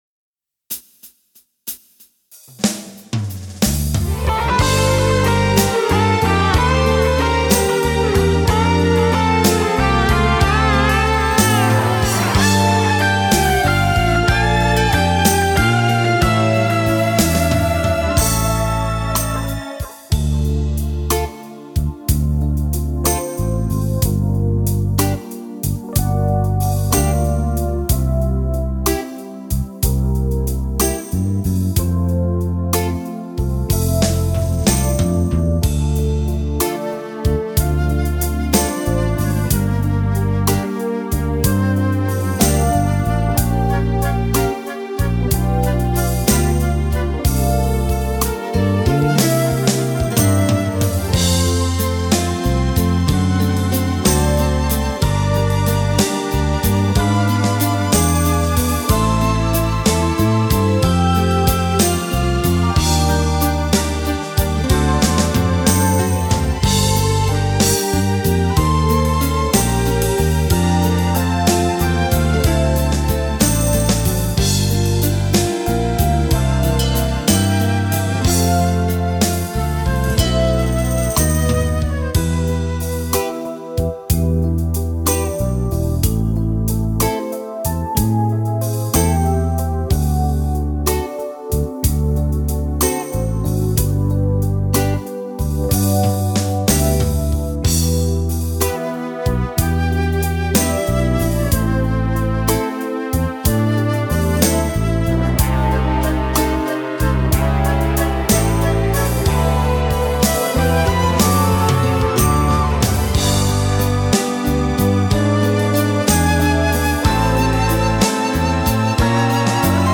Lento terzinato
Uomo